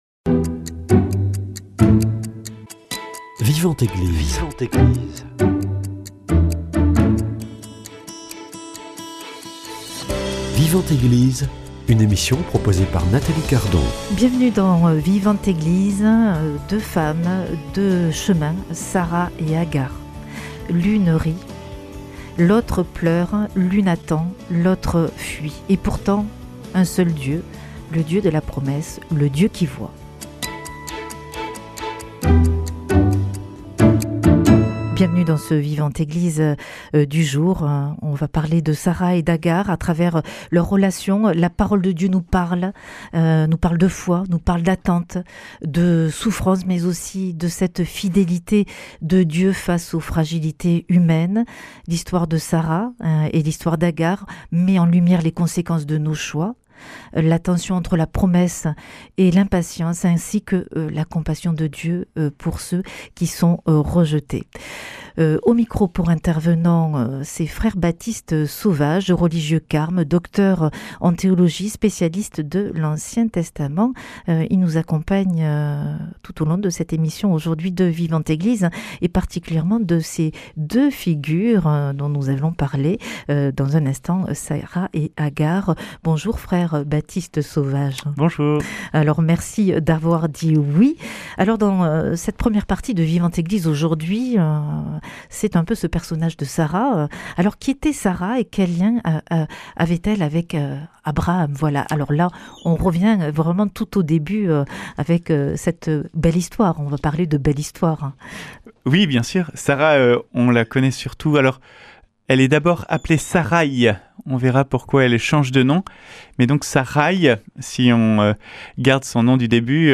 Vivante Eglise